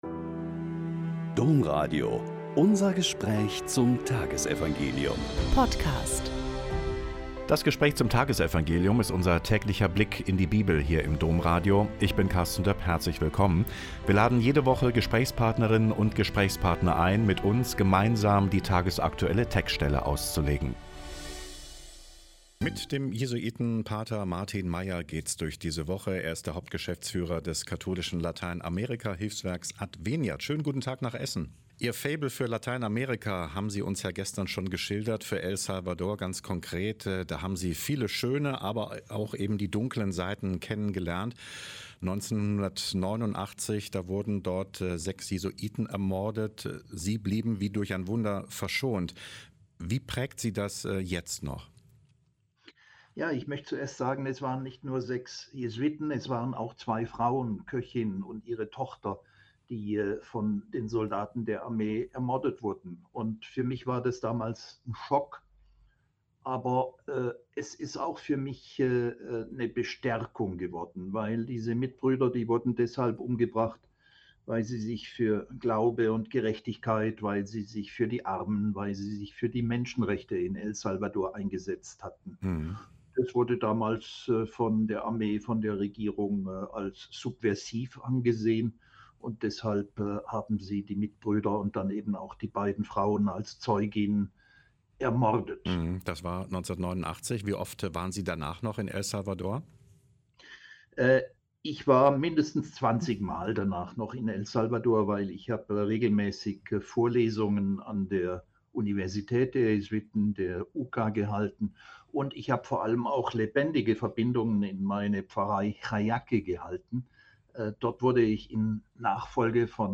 Mt 18,12-14 - Gespräch